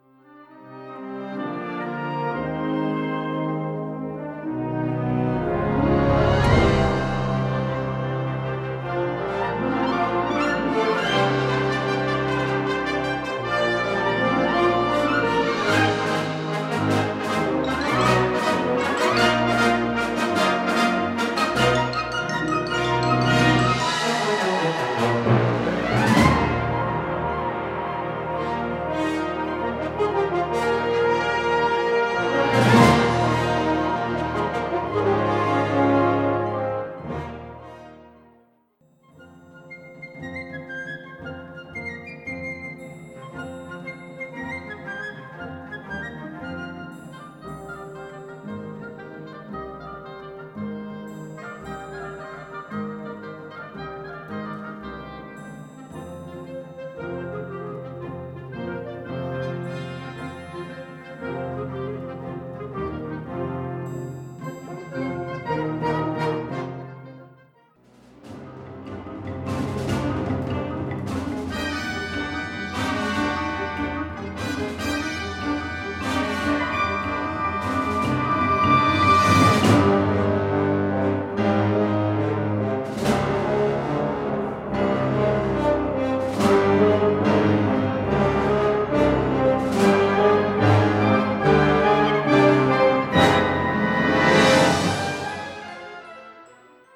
Subcategorie Hedendaagse blaasmuziek (1945-heden)
Bezetting Ha (harmonieorkest); CB (Concert Band)